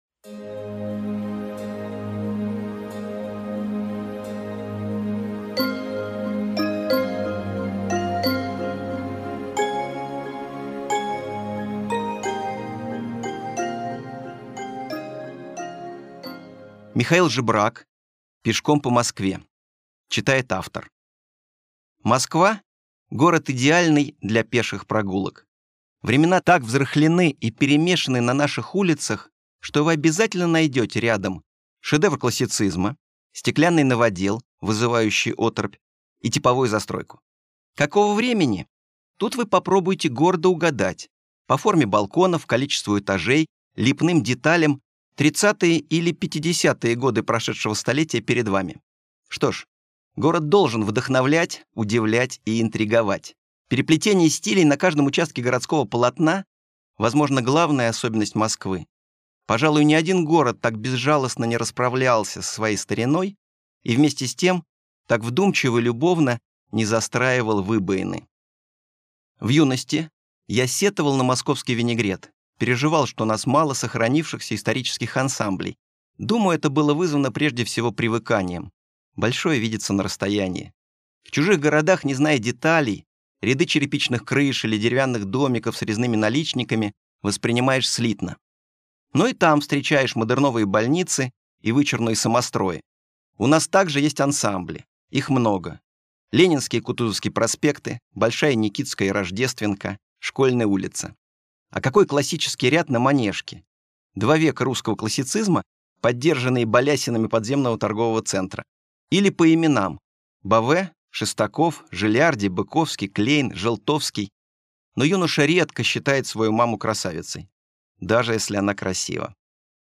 Аудиокнига Пешком по Москве | Библиотека аудиокниг